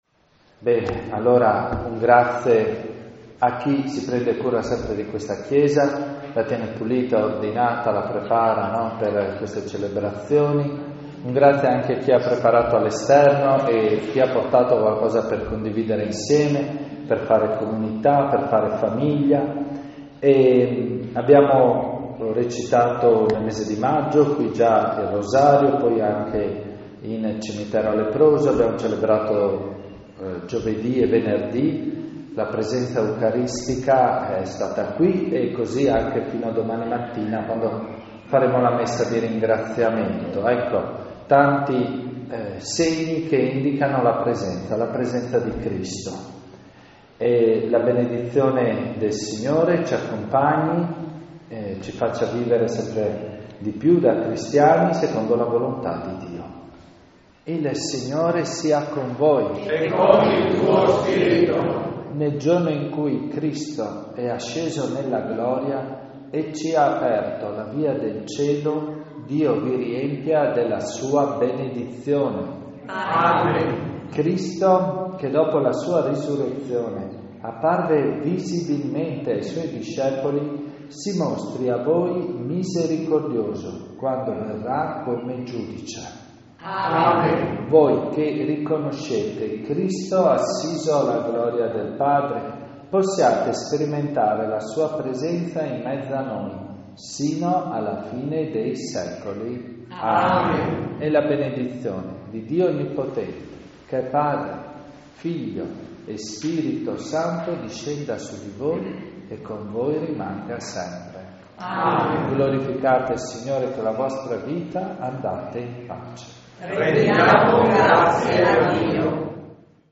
Messa Solenne nel giorno dell'Ascensione e Sagra di Leproso
accompagnata da una rappresentanza del Coro Interparrocchiale
CANTO E PREGHIERE DI APERTURA